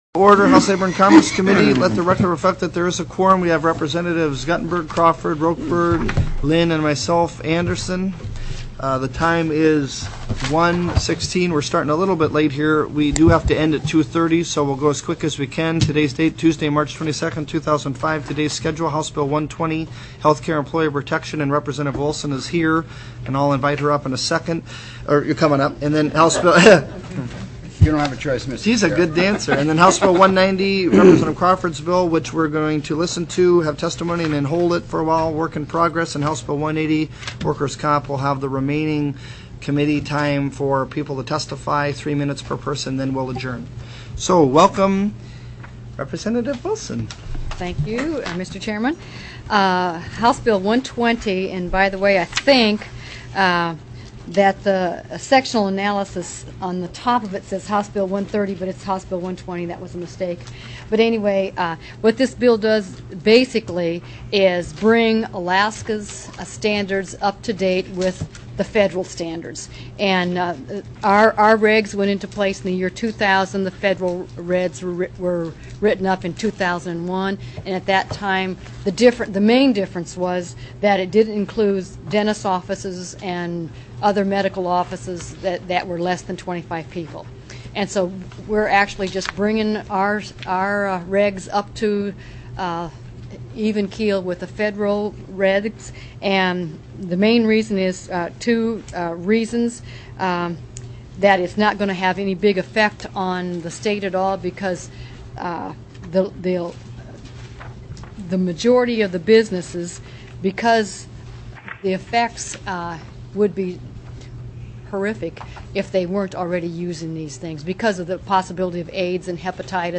03/22/2005 01:00 PM House LABOR & COMMERCE